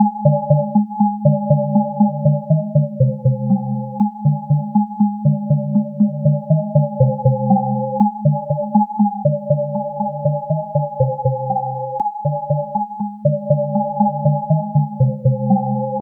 Lazy signal synthesis based on combinators. Provides a language embedded in Common Lisp to synthesize and modulate signals from scratch.